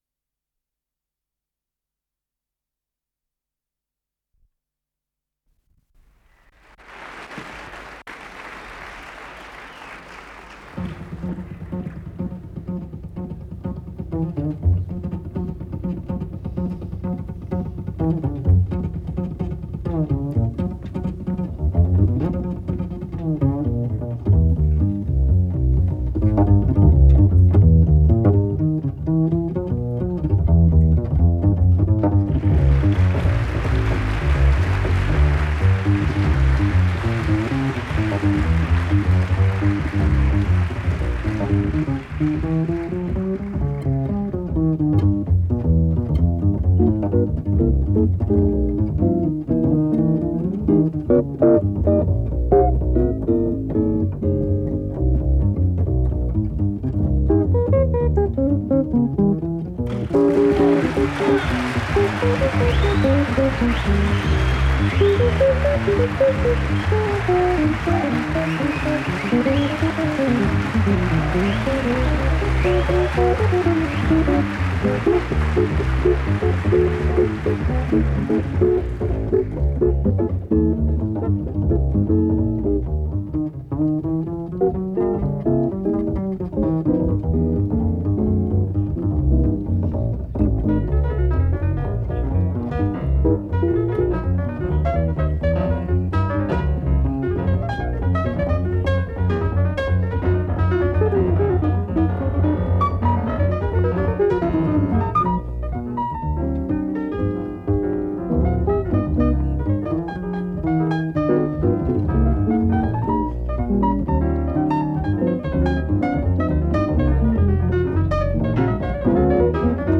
фортепиано
гитара
контарабс
ВариантДубль стерео